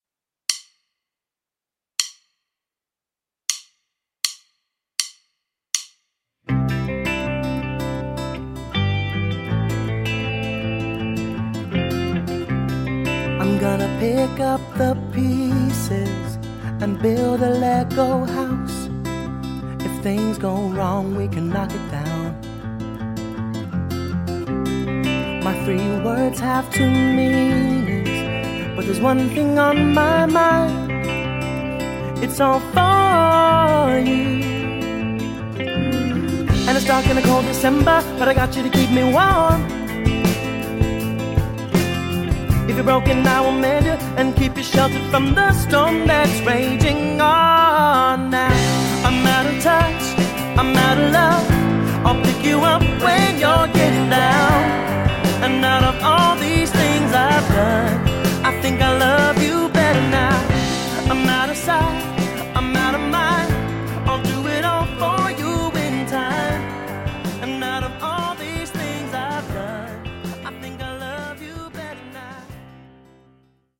sound-alike backing track
Drums Additional Information Instruments